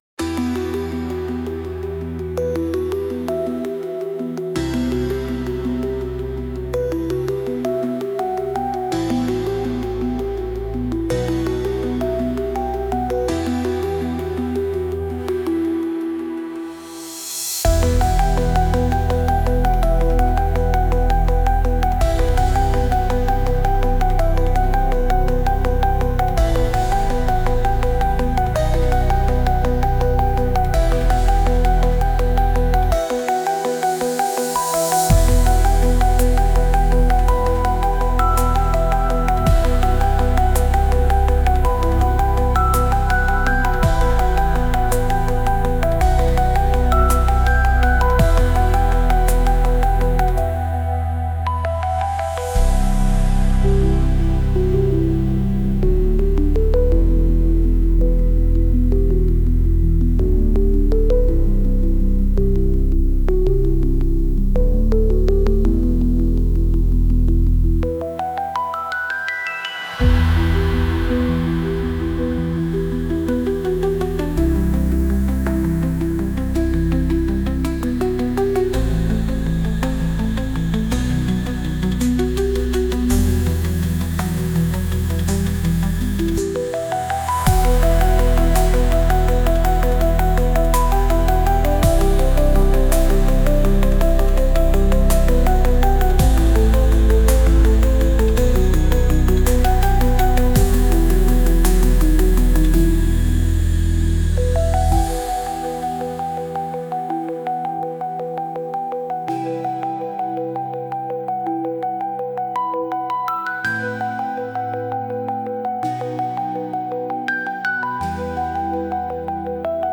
Instrumental -Unwrap the Day - Join Real Liberty Media PeerTube - DECENTRALIZED 4.29 .mp3 - Grimnir Radio